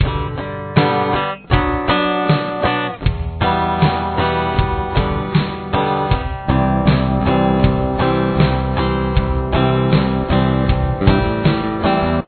Intro Riff
Here’s what the riff sounds like with guitar and bass: